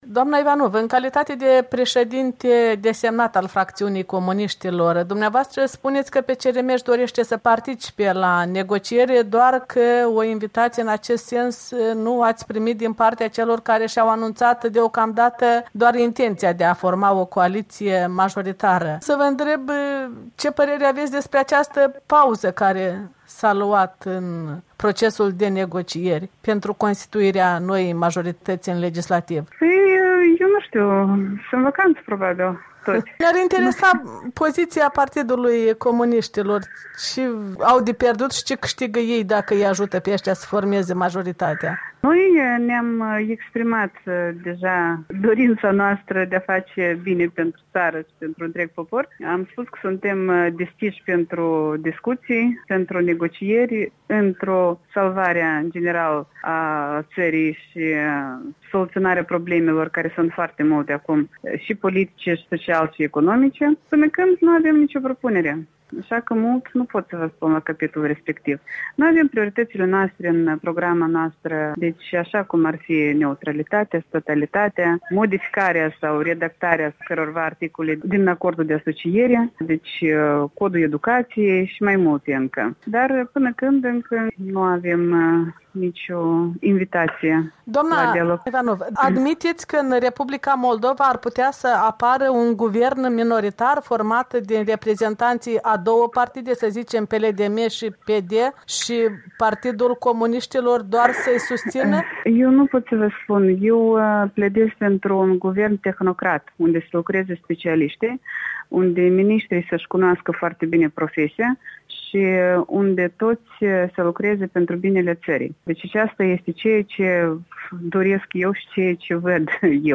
Un interviu cu Violeta Ivanov (PCRM)